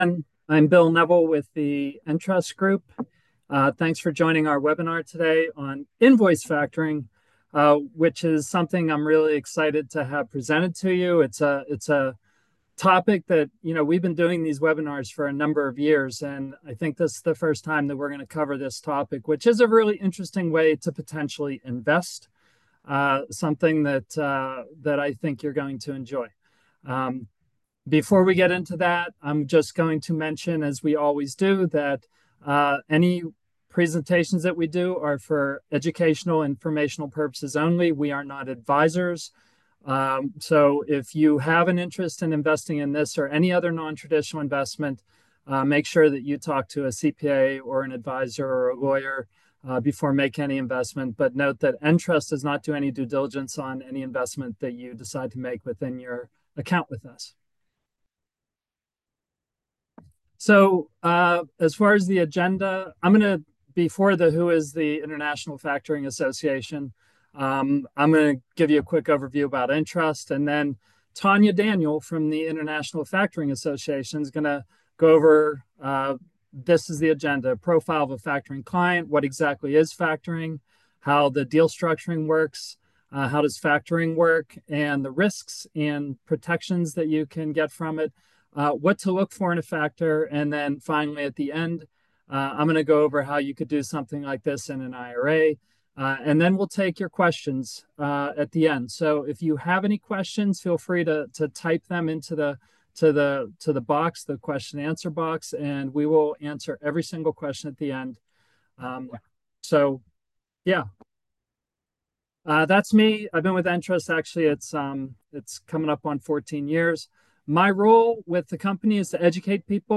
We host monthly webinars on a variety of topics.